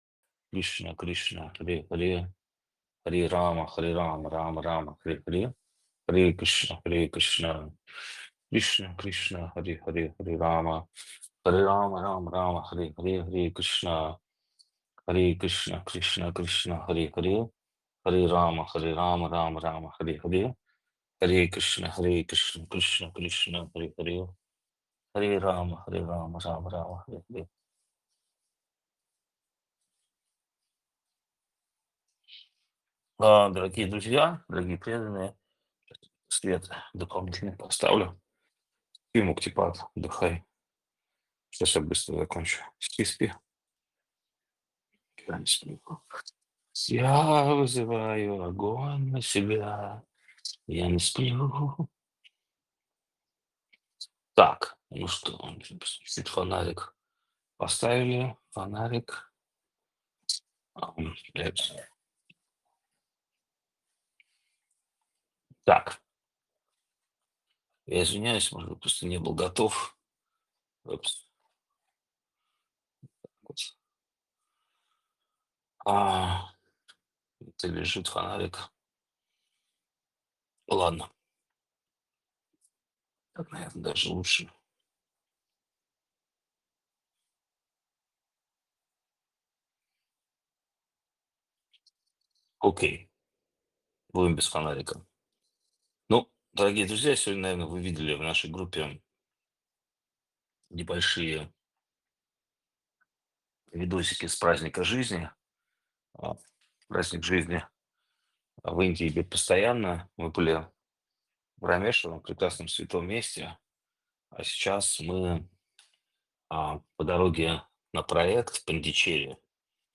Пондичерри, Индия
Лекции полностью